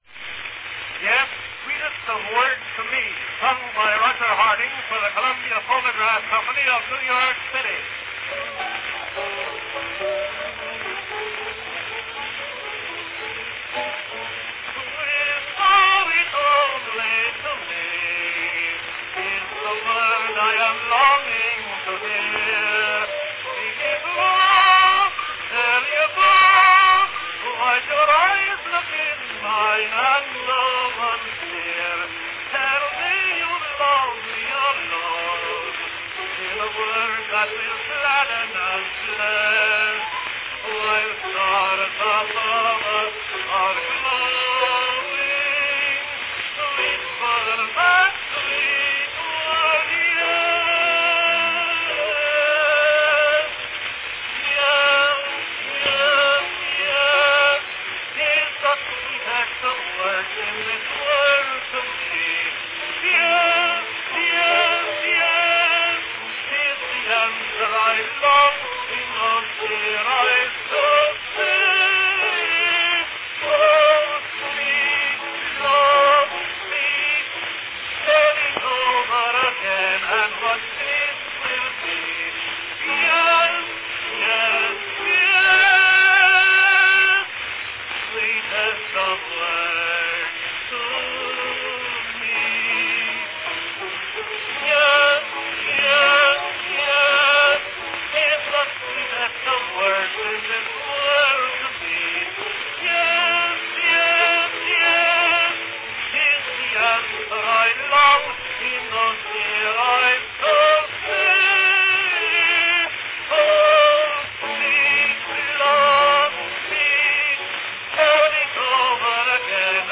Cylinder of the Month
Category Tenor